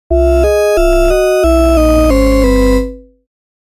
SE（呪い）
呪いをうけたときや敗北などのSEです。